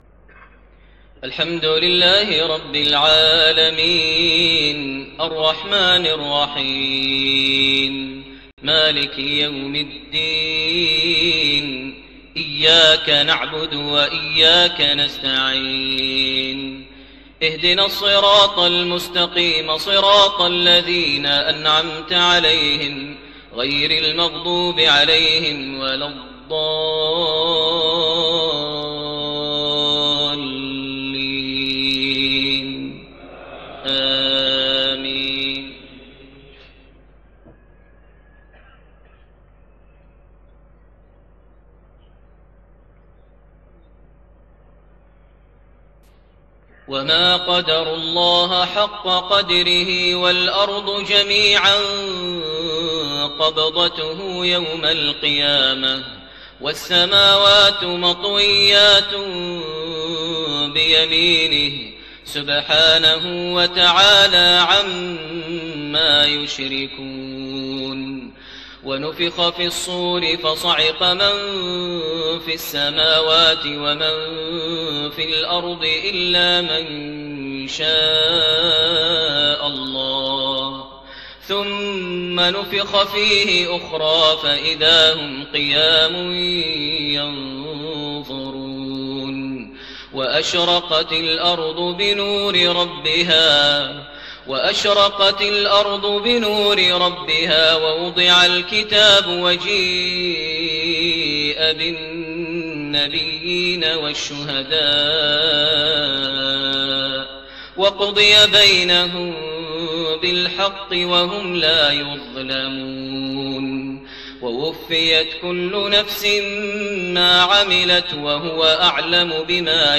صلاة المغرب1-5-1430 من سورة الزمر67-75 > 1430 هـ > الفروض - تلاوات ماهر المعيقلي